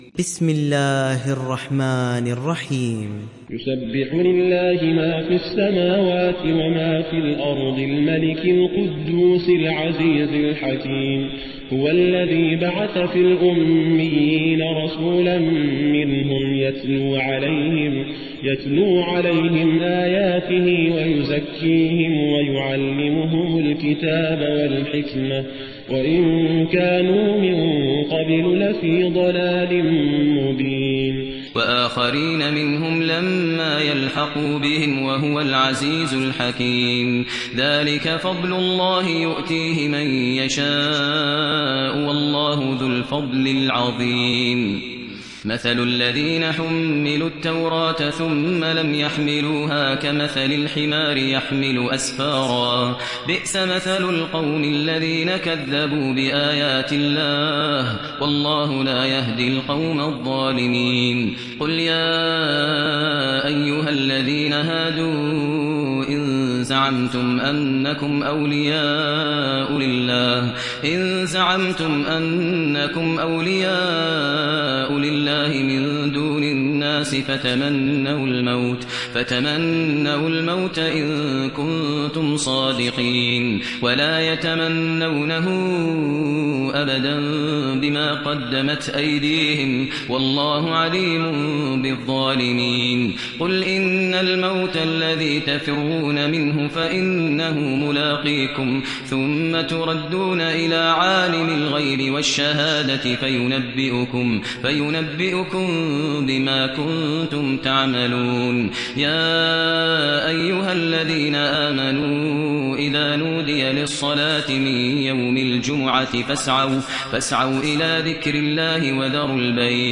সূরা আল-জুমু‘আ ডাউনলোড mp3 Maher Al Muaiqly উপন্যাস Hafs থেকে Asim, ডাউনলোড করুন এবং কুরআন শুনুন mp3 সম্পূর্ণ সরাসরি লিঙ্ক